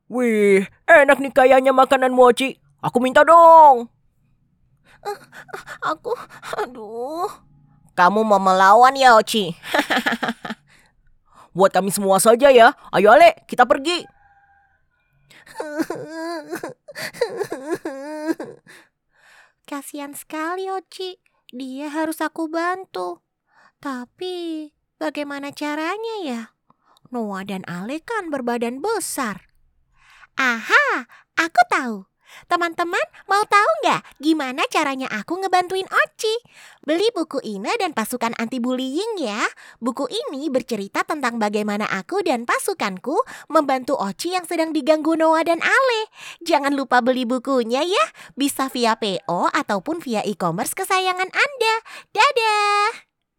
Commercieel, Diep, Volwassen, Warm, Zakelijk
Explainer